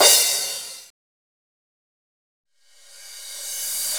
Session 14 - Crash.wav